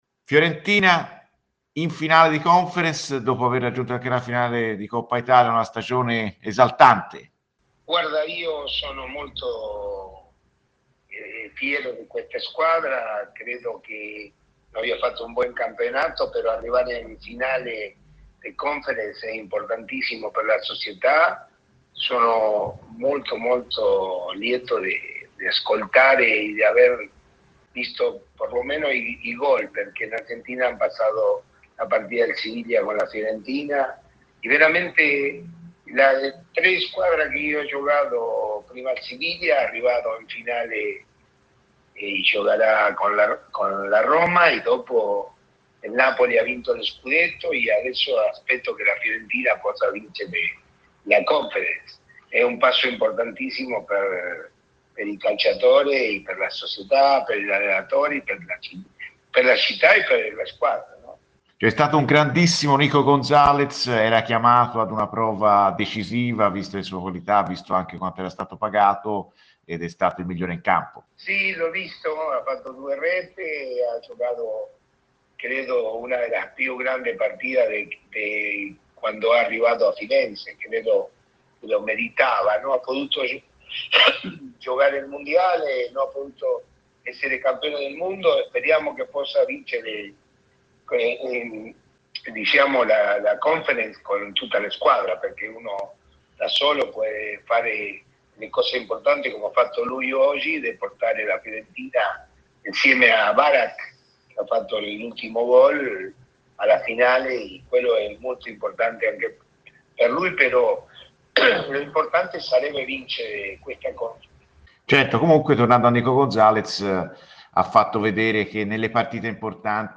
Daniel Bertoni, ex calciatore viola, è intervenuto ai microfoni di Radio FirenzeViola per parlare della stagione della Fiorentina: "Io sono molto contento di questa squadra, ha fatto un buon campionato, arrivare in finale di Conference è un gran traguardo per questa società. Sono lieto di aver visto almeno i gol di ieri della Fiorentina. È un passo importantissimo per tutti: società, giocatori e tifosi".